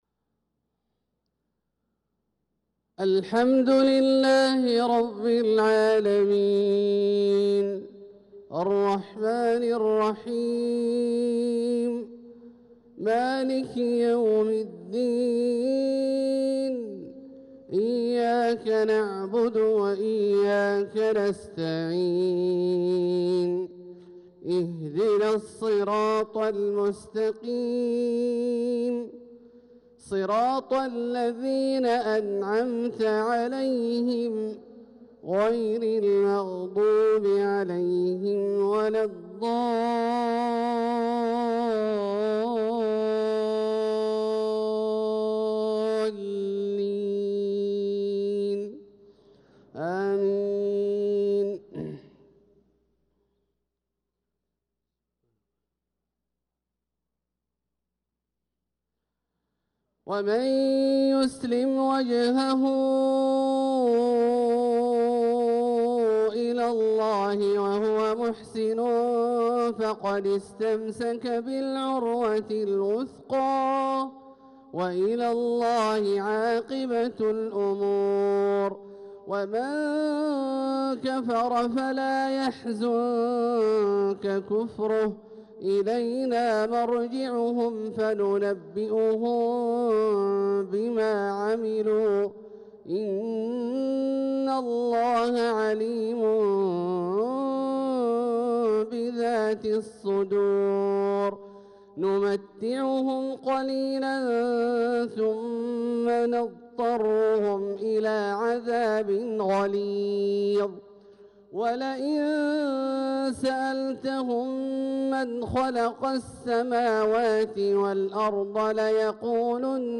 صلاة الفجر للقارئ عبدالله الجهني 22 ذو الحجة 1445 هـ
تِلَاوَات الْحَرَمَيْن .